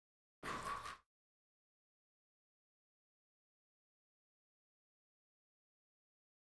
JumpRopeWhoosh WES031001
Cardiovascular Exercise; Rope Whoosh Without Any Floor Contact. Three Times.